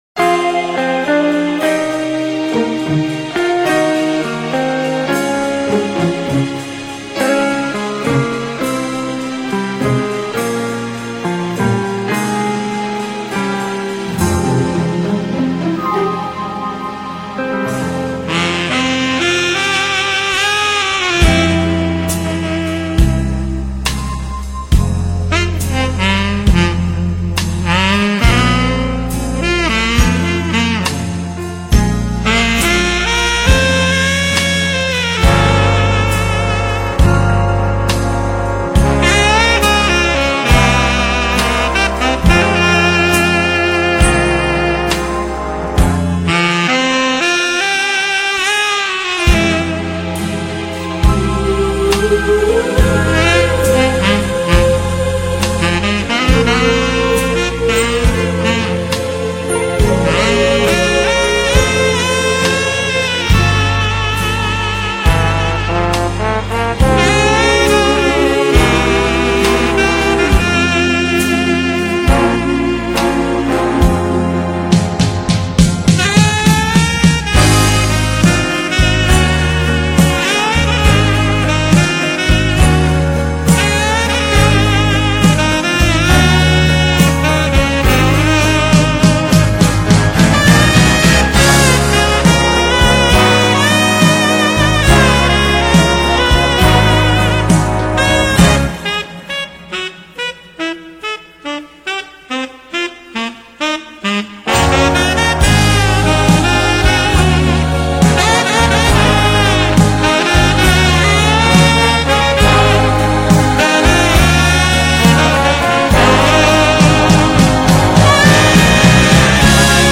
Instrumentais Para Ouvir: Clik na Musica.